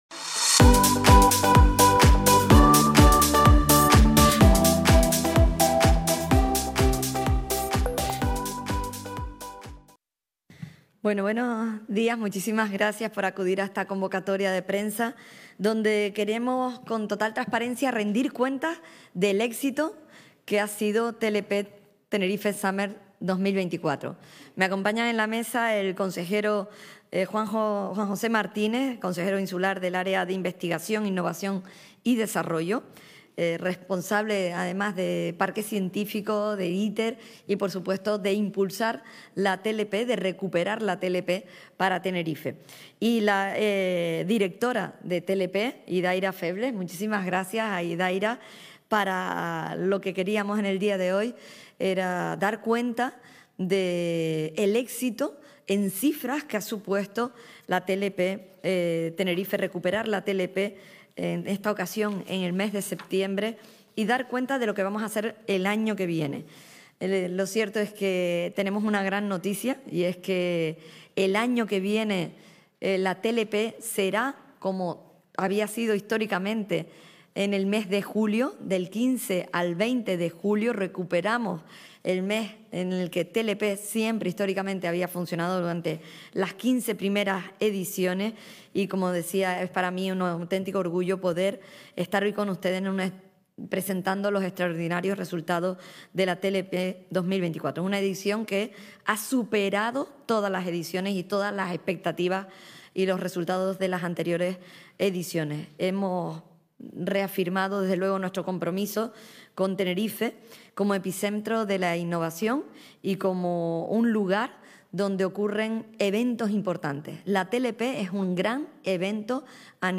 TLP Tenerife 2024 batió el récord de asistencia con la presencia de 62.535 personas en el Recinto Ferial del 3 al 8 de septiembre. Así lo anunció hoy (viernes 27) la presidenta del Cabildo de Tenerife, Rosa Dávila, que destacó el éxito de esta...